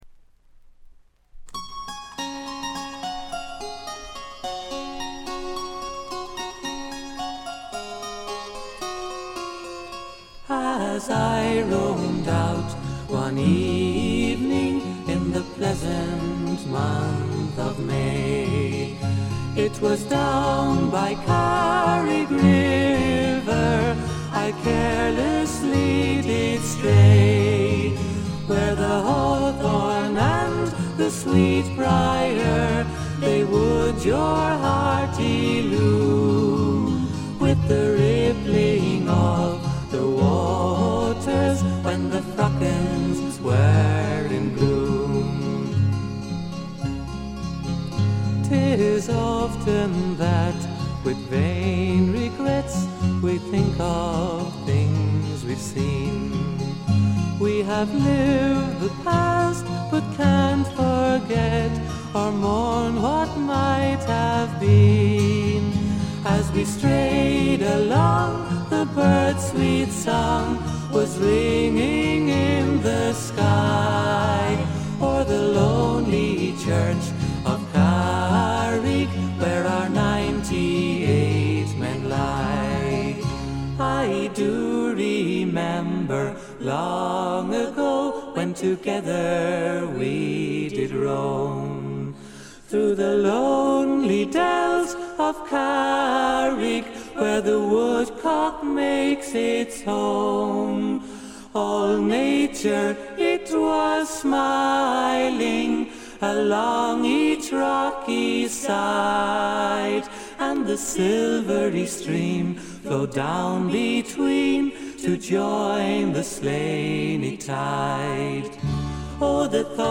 ほとんどノイズ感無し。
3人の美しいコーラスが聴きどころですが楽器演奏も基本的に3人でこなします。
試聴曲は現品からの取り込み音源です。
Eamonn Andrews Studios